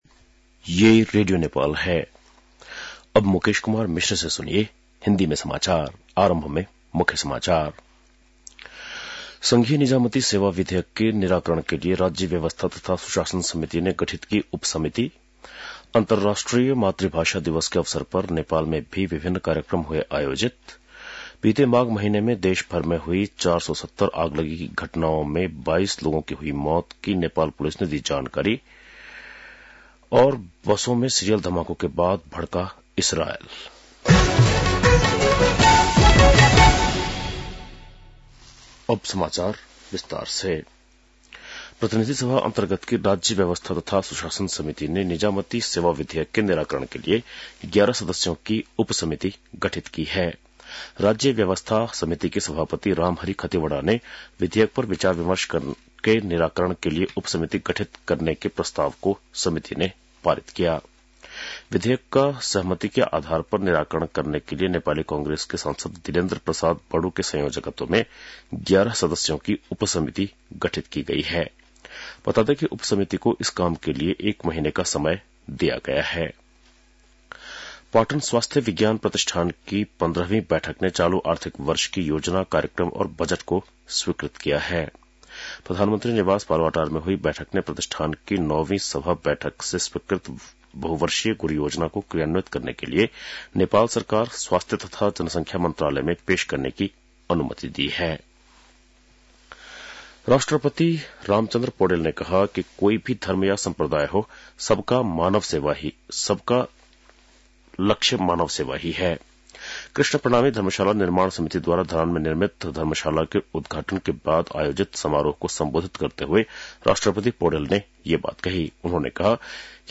बेलुकी १० बजेको हिन्दी समाचार : १० फागुन , २०८१